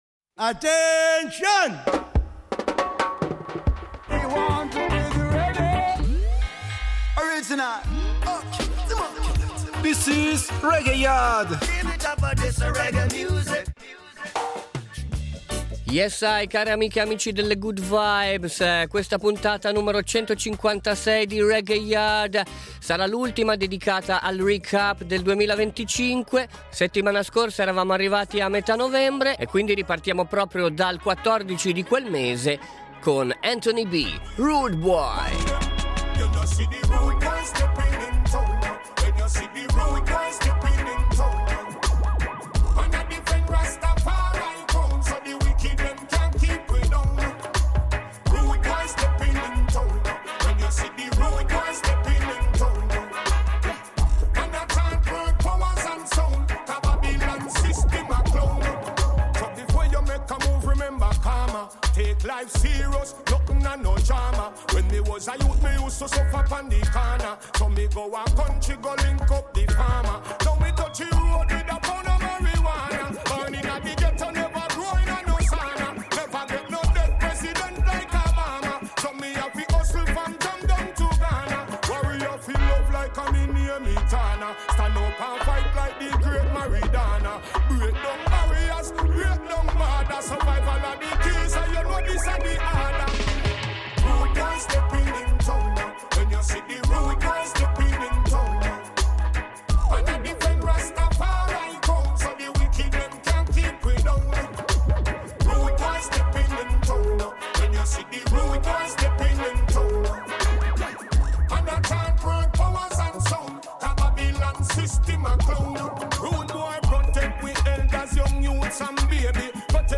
REGGAE / WORLD